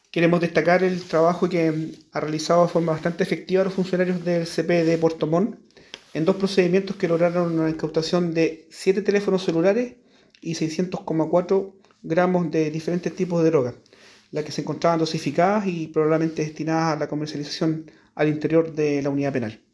El Director Regional de Gendarmería en Los Lagos, Coronel Edgardo Caniulef Gajardo, destacó el eficaz actuar de los funcionarios, lo que permitió sacar de circulación las sustancias y elementos prohibidos.